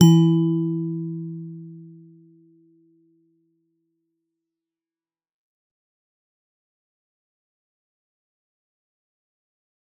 G_Musicbox-E3-f.wav